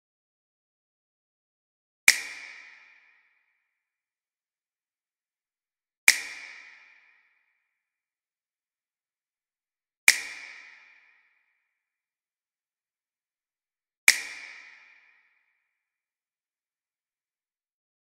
دانلود آهنگ بشکن 2 از افکت صوتی انسان و موجودات زنده
دانلود صدای بشکن 2 از ساعد نیوز با لینک مستقیم و کیفیت بالا
جلوه های صوتی